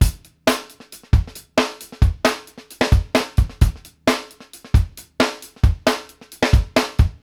FUNK 101  -L.wav